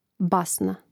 Rastavljanje na slogove: ba-sna